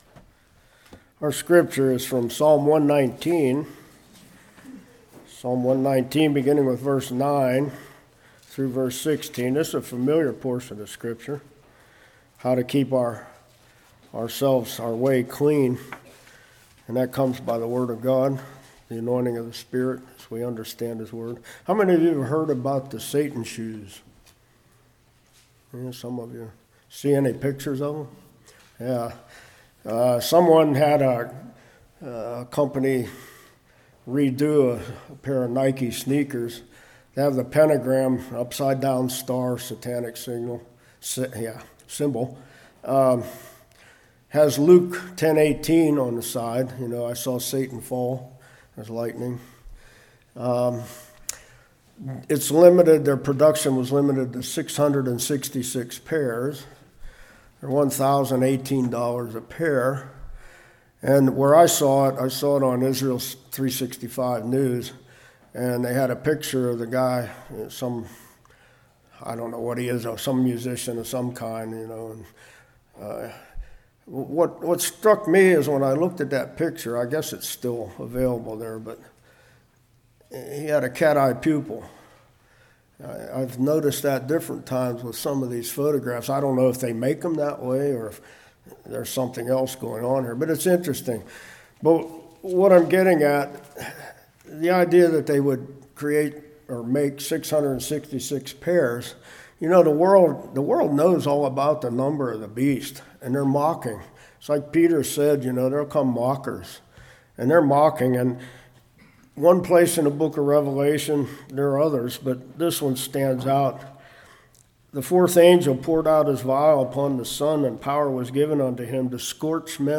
9-16 Service Type: Revival Keeping Thy Way Pure Obeying The Word Memorization Meditation Are Family Devotions Important?